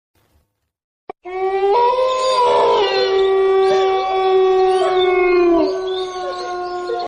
Download Haunting Halloween sound effect for free.
Haunting Halloween